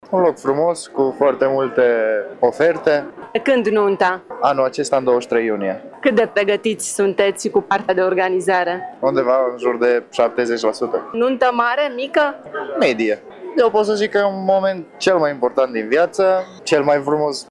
Expoziția Nunta Noastră și-a deschis porțile în Cetatea Tîrgu Mureș cu toată gama de servicii și produse specifice petrecerilor.
Cuplurile sunt încântate de ce văd și consultă cu interes sfaturile specialiștilor pentru nunta care se apropie: